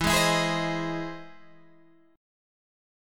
E+M7 chord